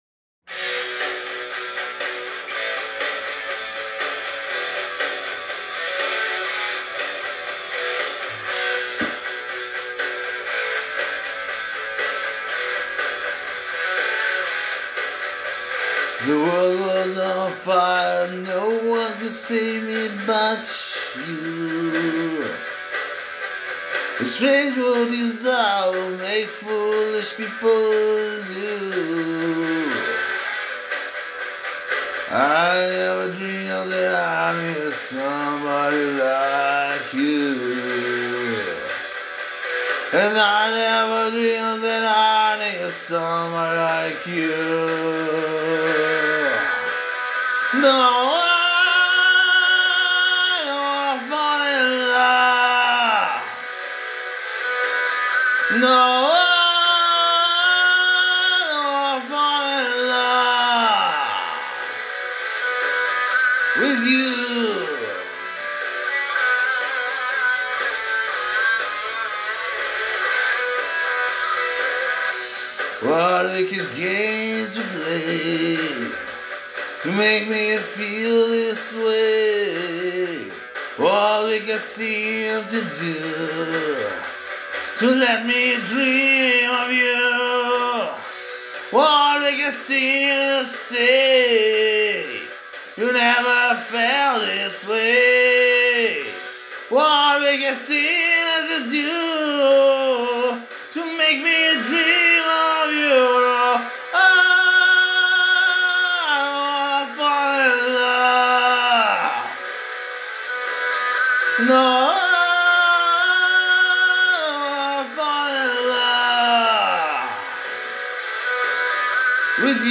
Я насладился своим пением.
ТИП: Пісня
СТИЛЬОВІ ЖАНРИ: Романтичний